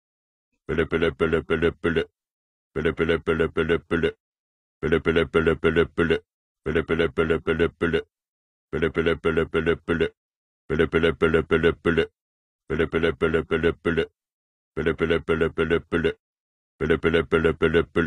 ring-viejo.ogg